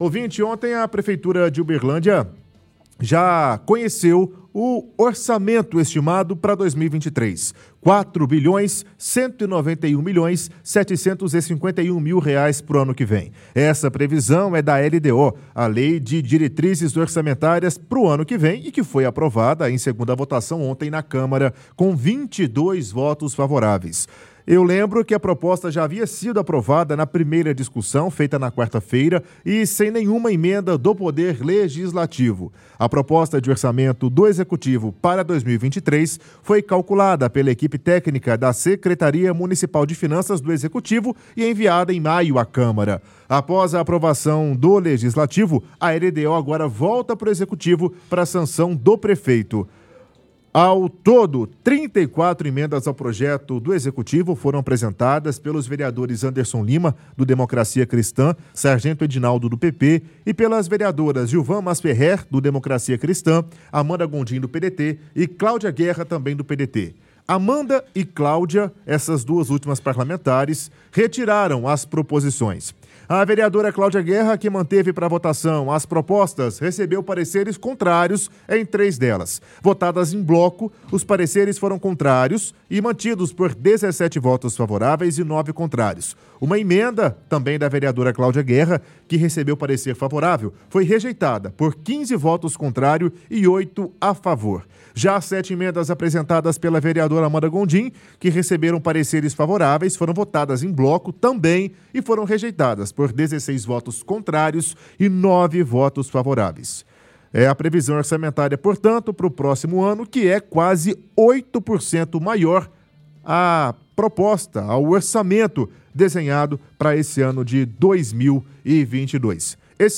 – Apresentador lê reportagem do G1 sobre a aprovação da LDO pela câmara.